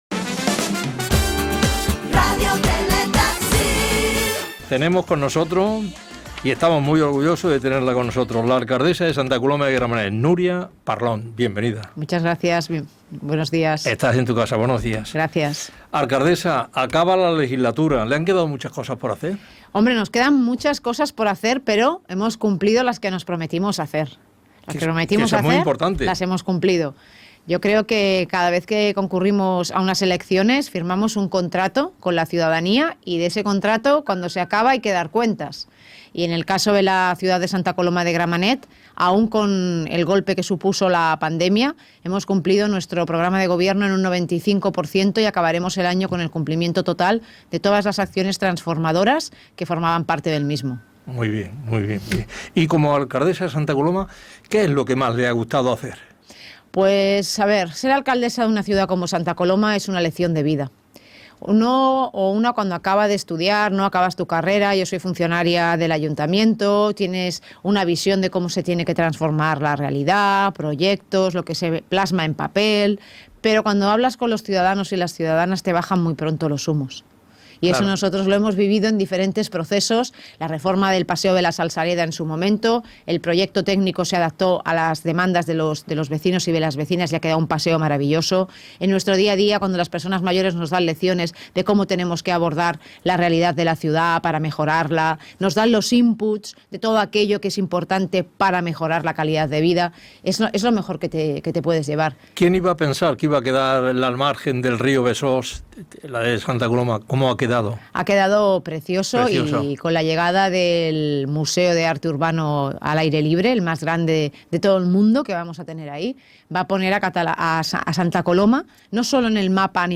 Indicatiu de l'emissora i entrevista a l'alcaldessa de Santa Coloma de Gramenet Nuria Parlón que es tornava a presentar a les eleccions municipals
Entreteniment